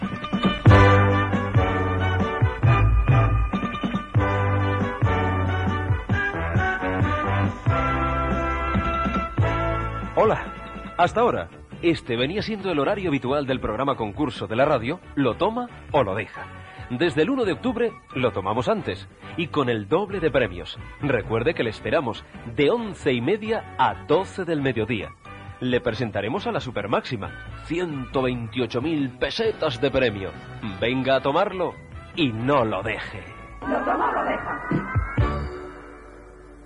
Promoció amb l'avís del canvi d'horari en la nova temporada (1984-1985).